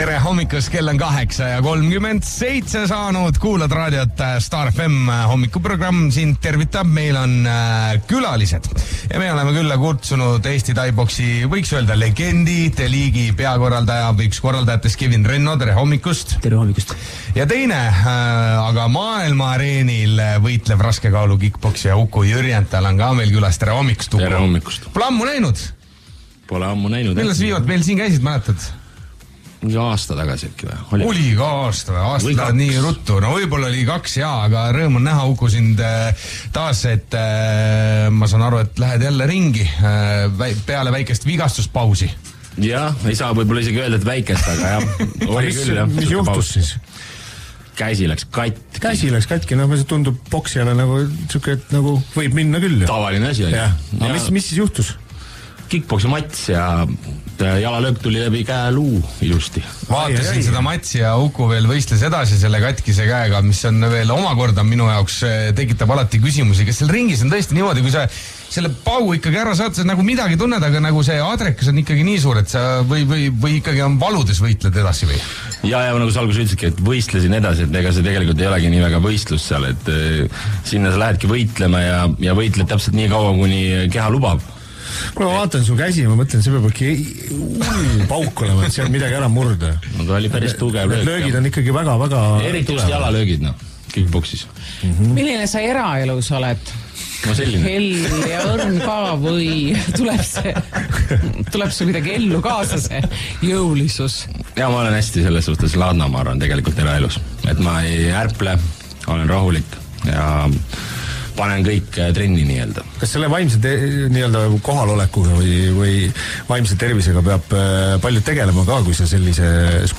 Star FM hommikuprogrammis